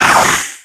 Audio / SE / Cries / ZANGOOSE.ogg